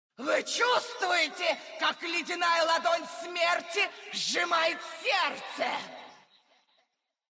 Когда босс применяет какую-нибудь свою способность он издает при этом определенные звуки или говорит «дежурные» фразы, порой весьма раздражающие… smile Рассмотрим это на примере босса Цитадели Ледяной Короны – Синдрагосы.
Использование способности «Обжигающий холод»: